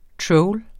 Udtale [ ˈtɹɔwl ]